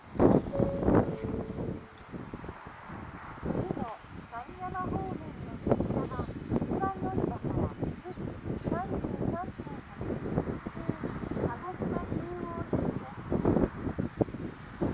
この駅では接近放送・予告放送が設置されています。
１番のりば指宿枕崎線
予告放送普通　鹿児島中央行き予告放送です。